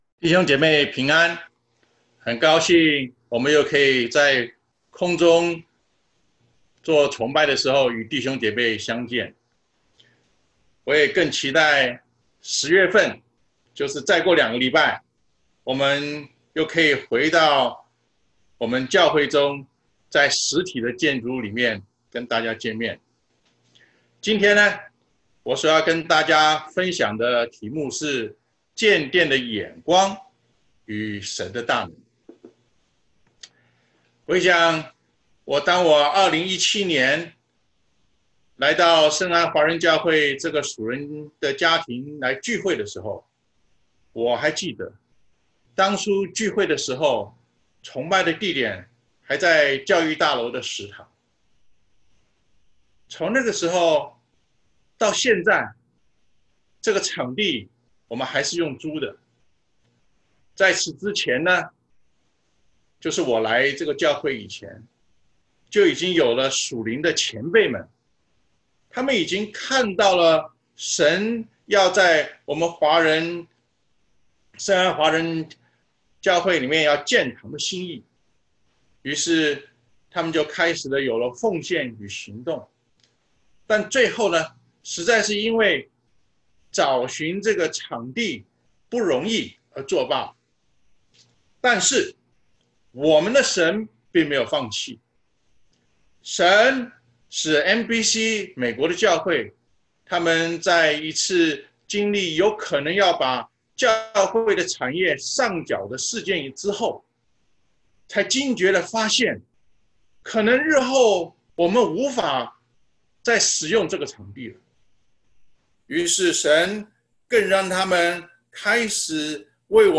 Zechariah 4:4-9 Service Type: Sunday Service 馬太福音6:33